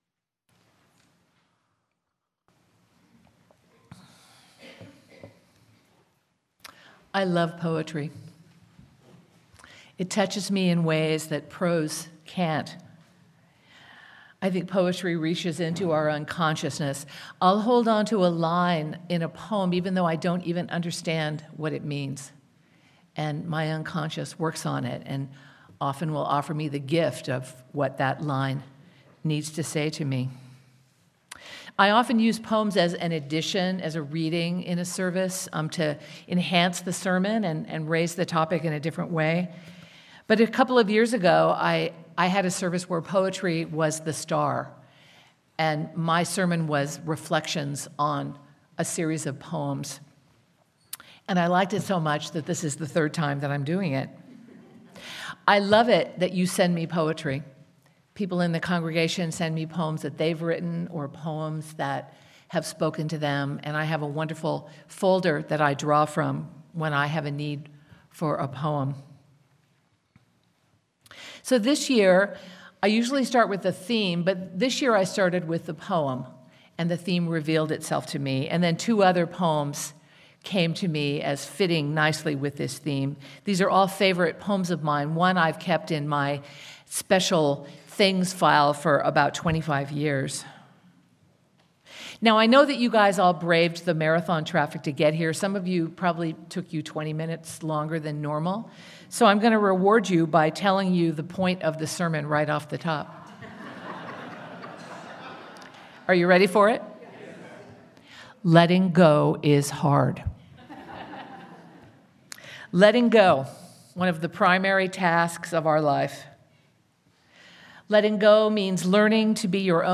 Sermon-To-Have-Without-Holding.mp3